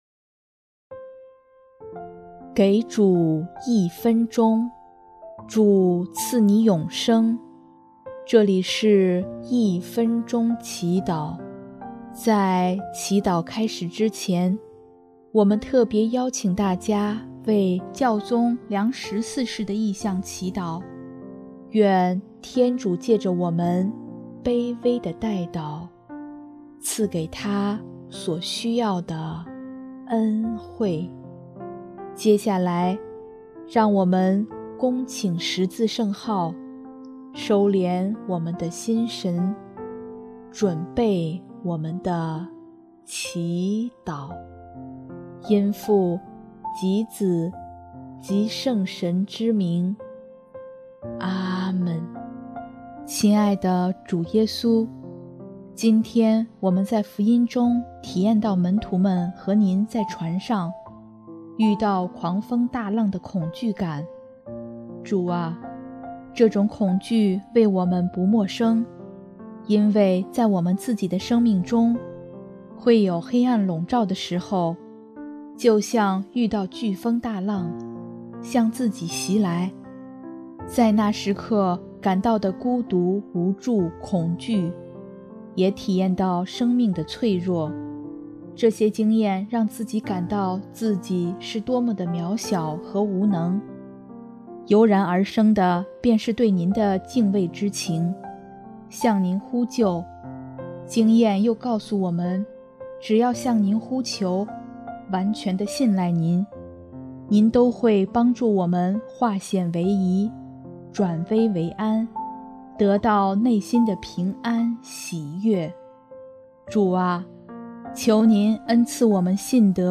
【一分钟祈祷】|7月1日 主啊！求您恩赐我们信德！
音乐： 主日赞歌《祂使风平浪静》（教宗良十四世：愿我们愈发学会分辨，知道如何选择人生的道路，并拒绝一切使我们远离基督和福音的事物。）